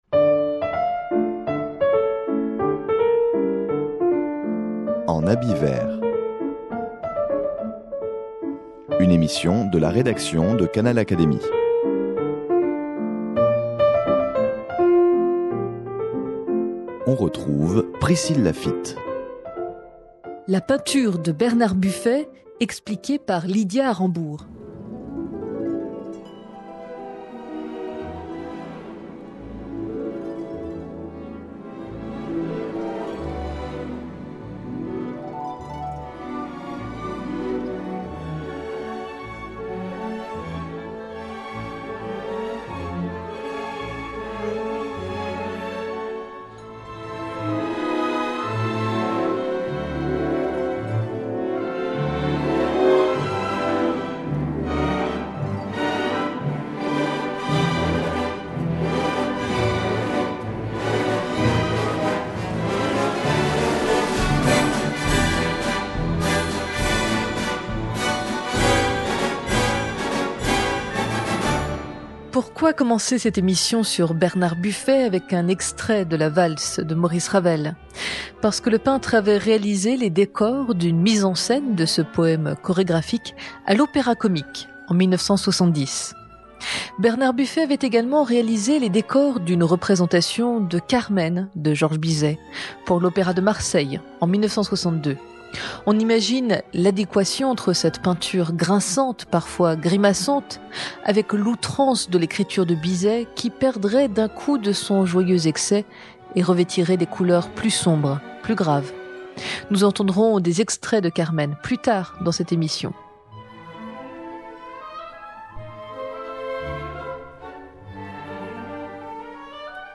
Emission réalisée dans la Galerie Maurice Garnier, entièrement consacrée à Bernard Buffet.